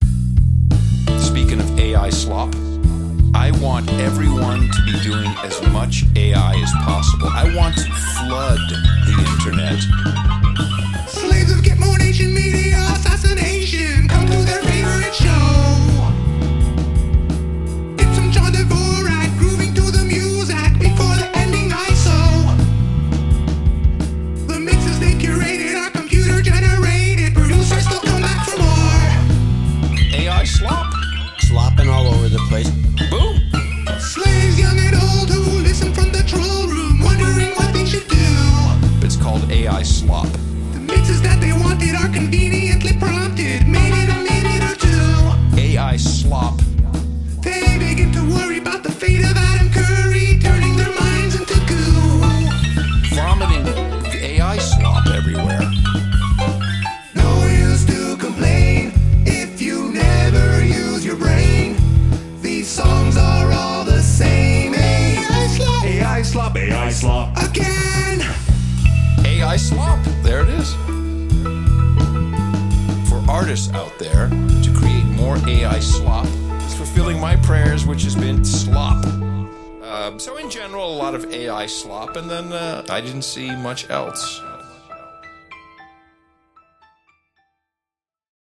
End of Show Mixes: